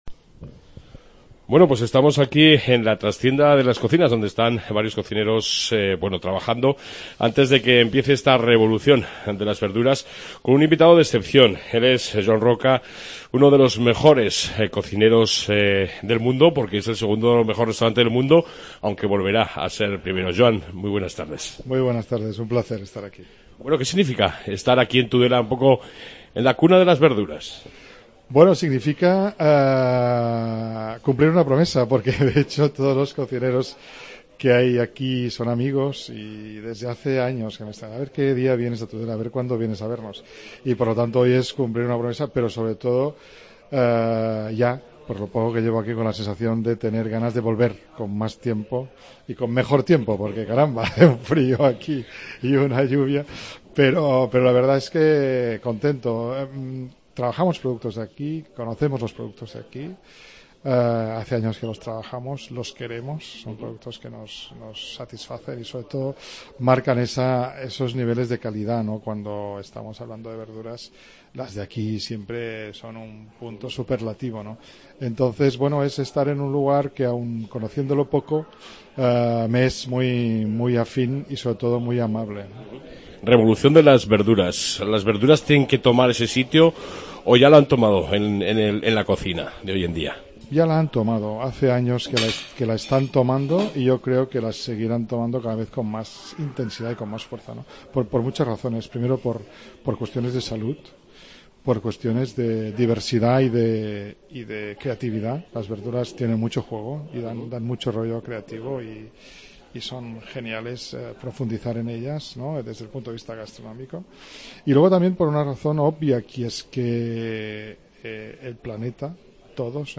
AUDIO: Entrevista con El gran cocinero Joan Roca...